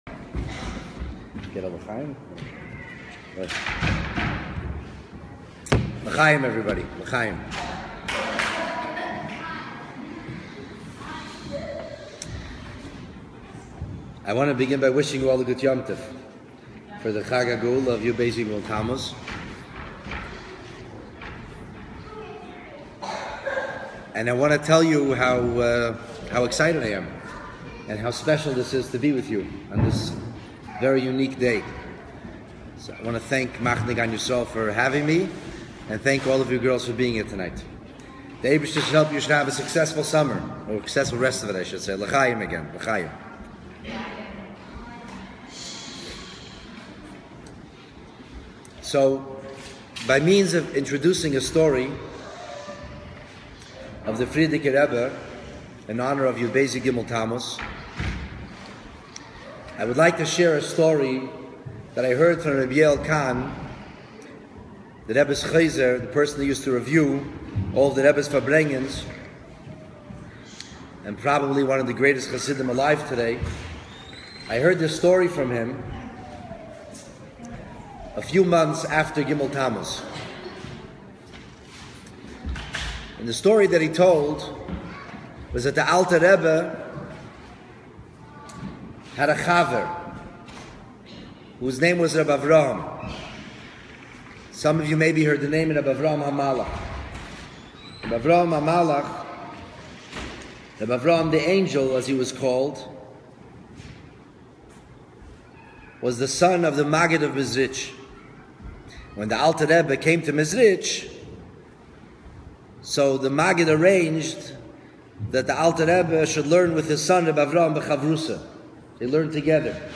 Yud Bais Tammuz Farbrengen - Live from Camp Gan Yisrael in HALIBURTON (Toronto)